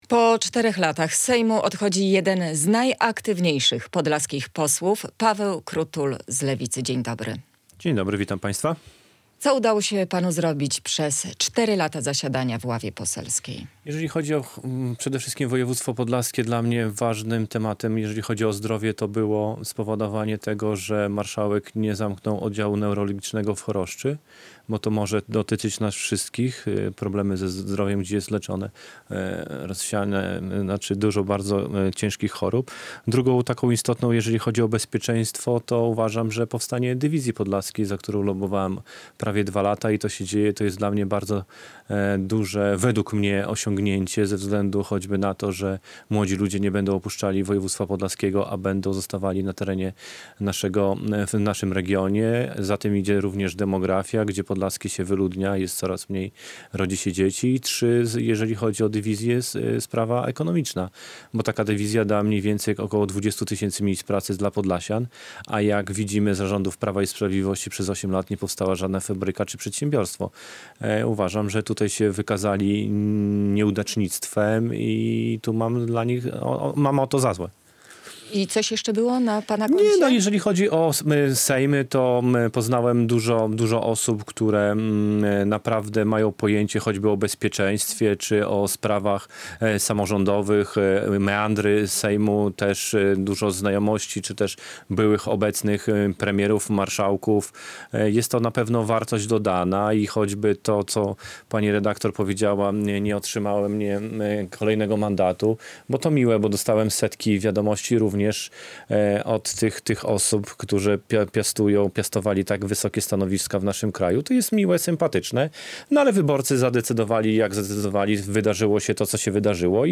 Radio Białystok | Gość | Paweł Krutul [wideo] - poseł Lewicy